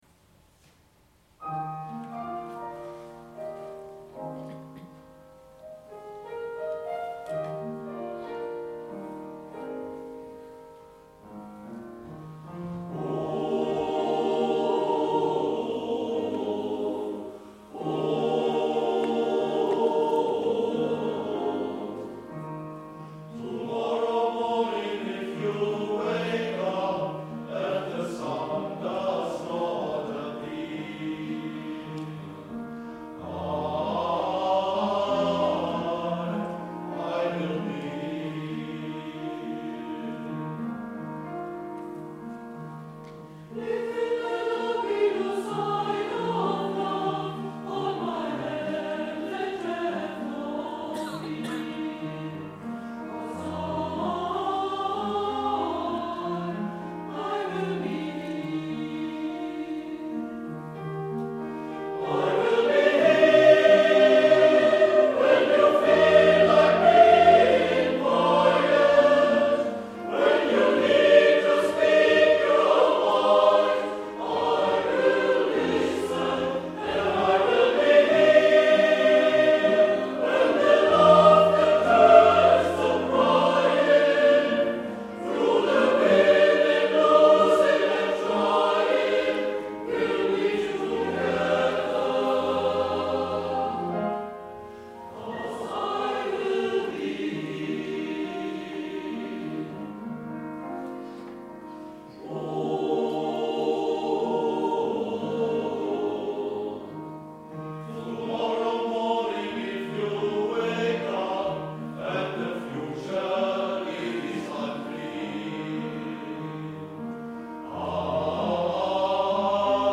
Repertoire – molto vocalis – Der Mössinger Chor
Durch Zeit und Ewigkeit, Mössingen 2024